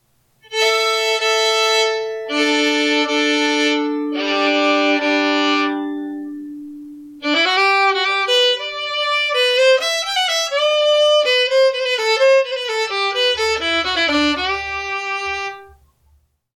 Vintage Lyon & Healy Violin/Fiddle $1565
What a great hoedown fiddle!
I would classify this as one loud in volume, with a smooth, moderately bright tone quality. Even across all strings! Great for bluegrass and will get you heard in any acoustic jam setting!